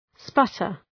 Shkrimi fonetik {‘spʌtər}